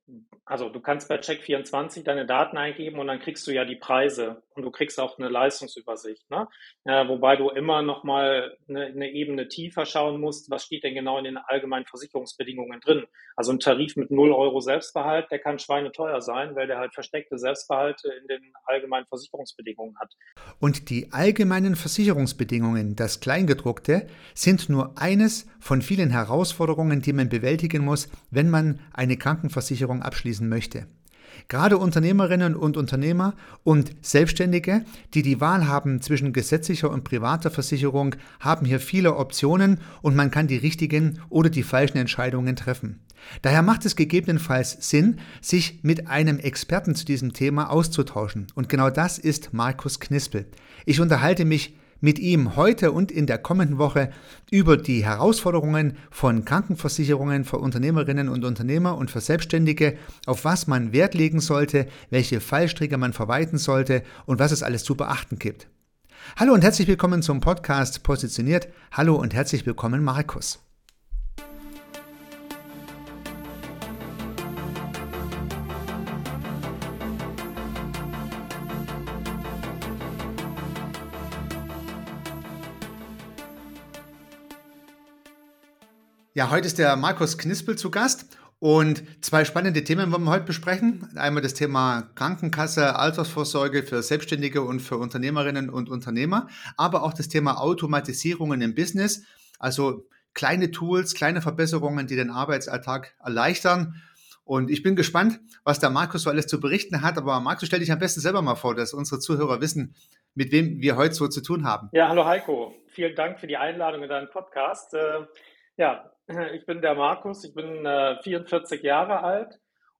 P043 KRANKENVERSICHERUNG für Selbstständige (I) - das Gespräch mit dem Experten